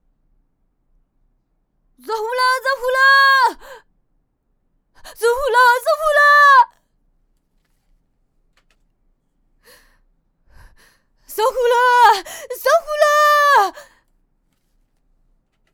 c02_小孩喊着火啦.wav